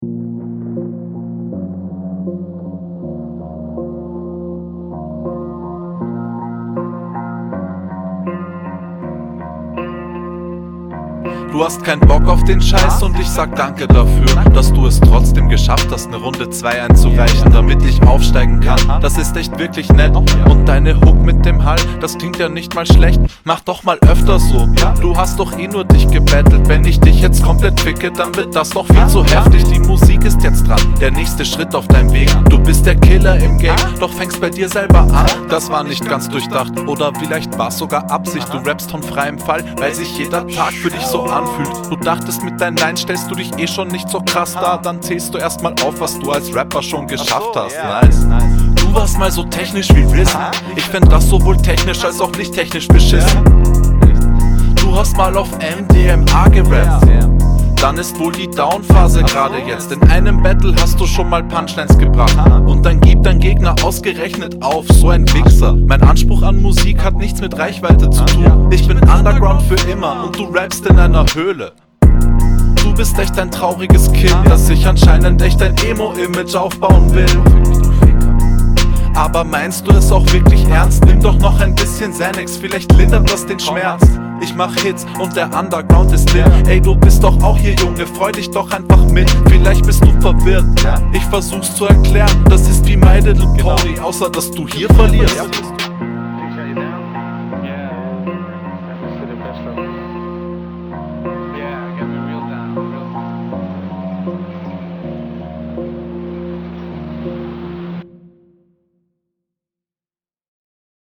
Es ist halt wieder so herrlich von oben herab.
Ich finde den Stimmeinsatz wieder ziemlich cool, delivert ist das solide, alles ist im Takt …